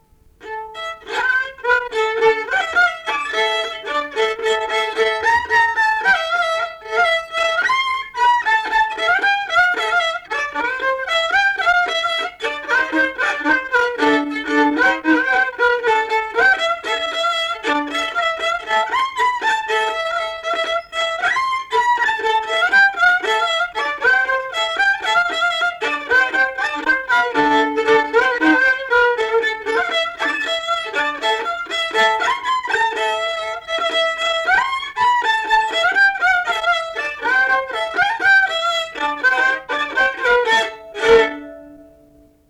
Valsas - fokstrotas
Erdvinė aprėptis Ukmergė
Atlikimo pubūdis instrumentinis
Instrumentas smuikas